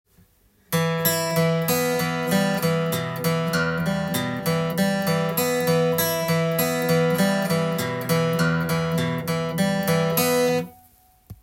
４弦強化
アルペジオのTAB譜を作ってみました。
Emでアルペジオをして弾いていきますが